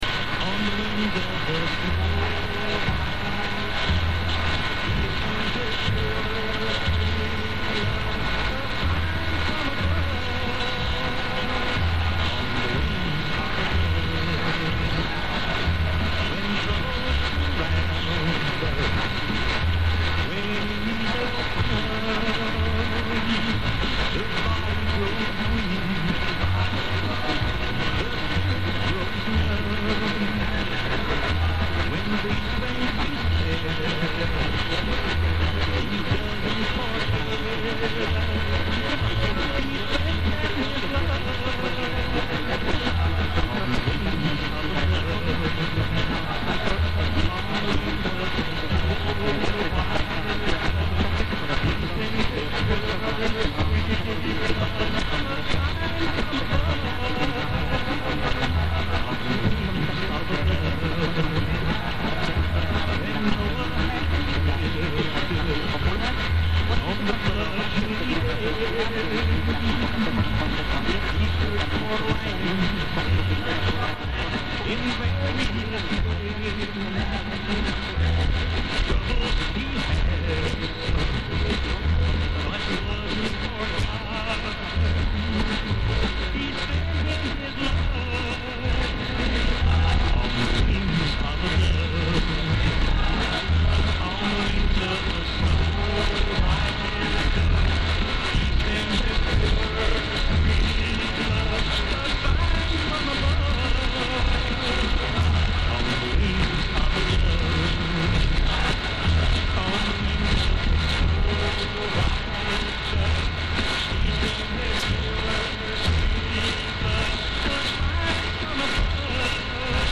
...folytatom az "AM STEREO"-t...teljesen véletlenül találtam...999 kHz-en egy harmat gyenge adónál c-quam szinkront vettem...egy olasz adó alól alig tudtam kiszedni...épp aktuális zenei összeállítás volt műsoron...egy részlet - nagyon gyenge minőségű, a felvétel elején RF erősítéssel, AGC-vel játszottam (hallható zaj), a végén annyira legyengült a vétel, hogy szétesett a "szinkron"...